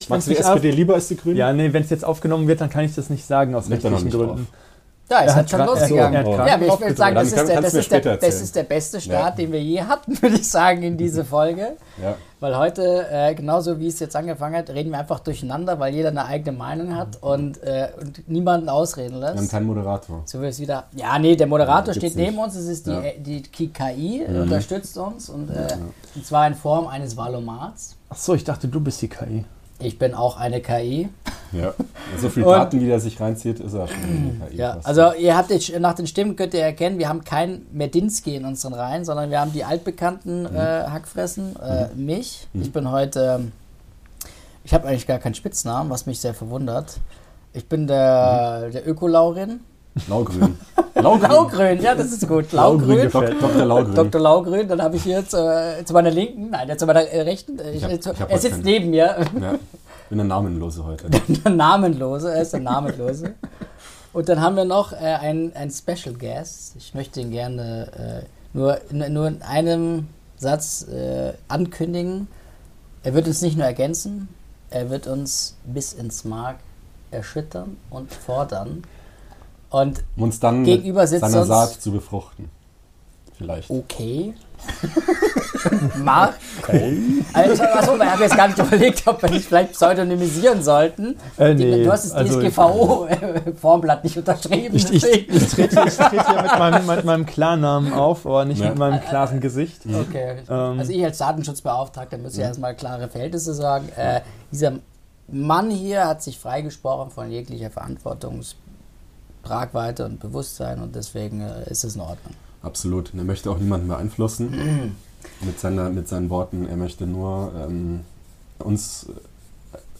Hört rein in den Trialog dreierlei Perspektiven in Bezug auf wichtige politische Fragen auf Basis des Wahl-O-Maten!